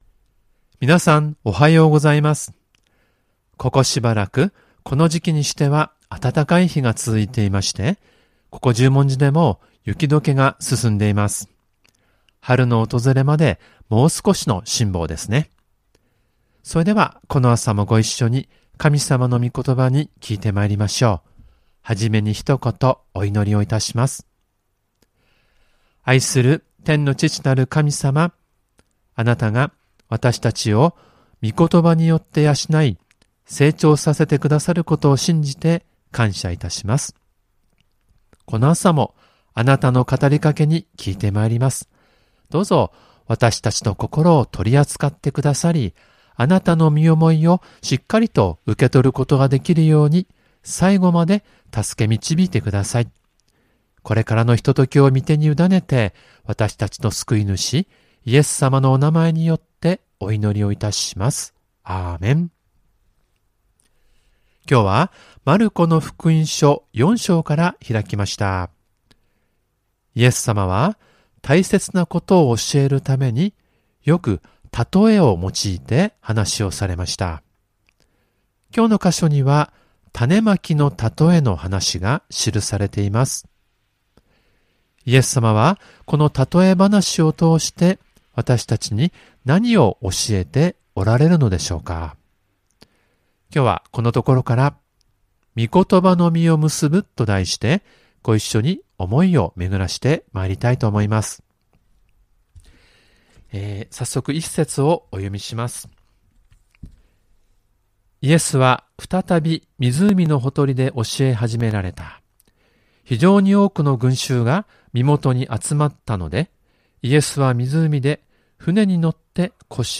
●主日礼拝メッセージ（赤文字をクリックするとメッセージが聴けます。